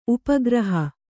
♪ upa graha